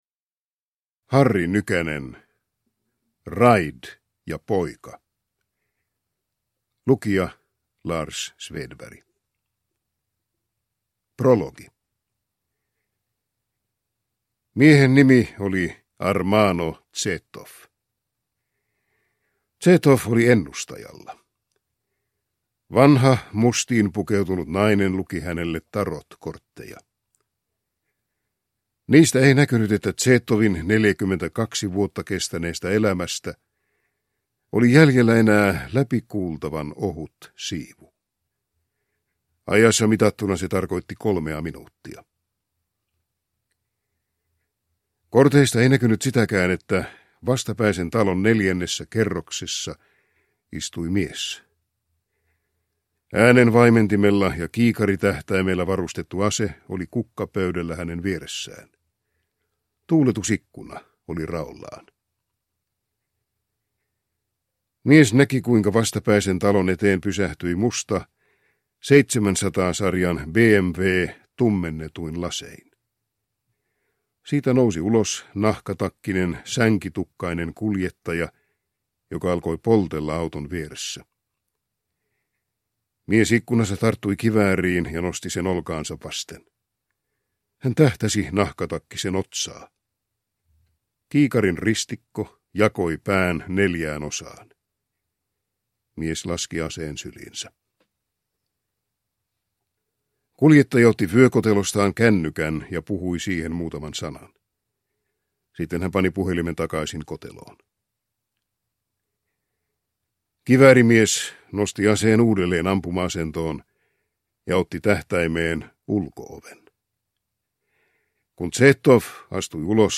Raid ja poika – Ljudbok – Laddas ner